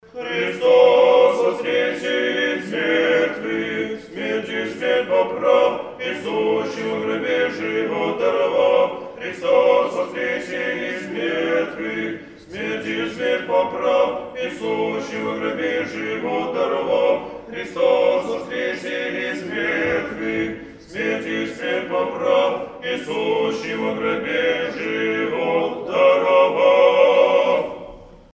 За Литургией пел малый состав хора